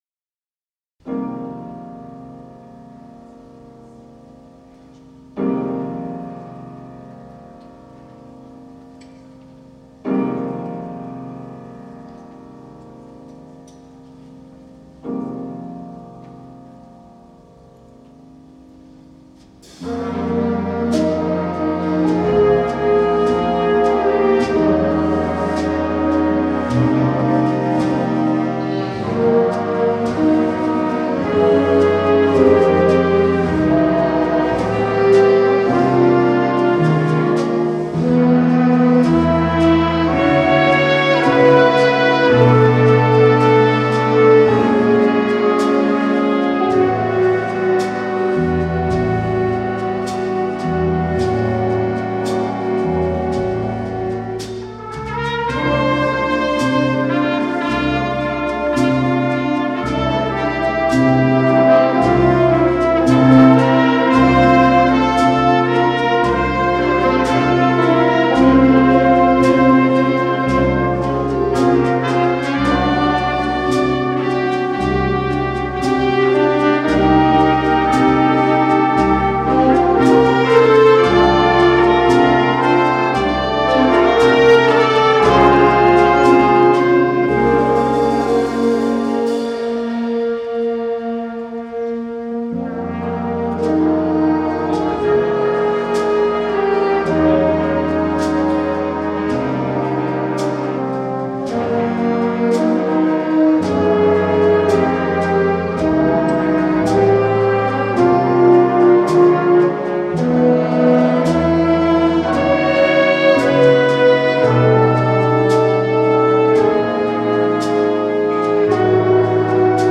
Jazz Concert 2023 Recordings